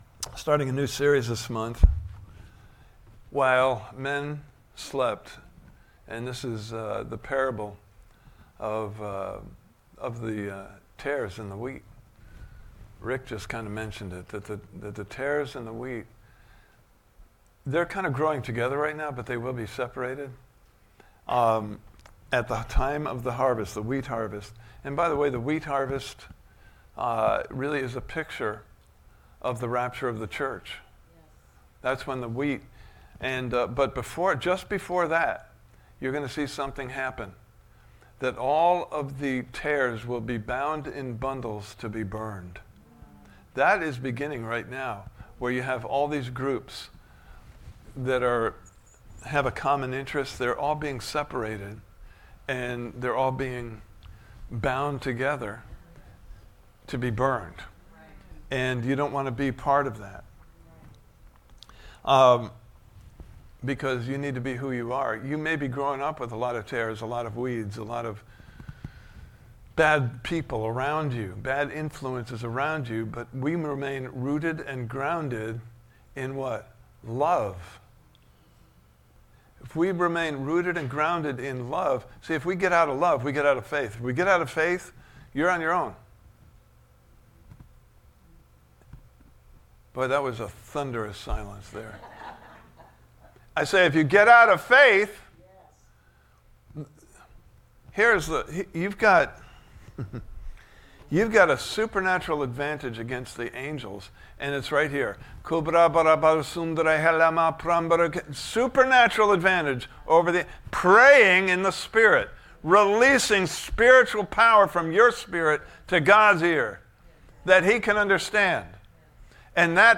While Men Slept Service Type: Sunday Morning Service « Part 5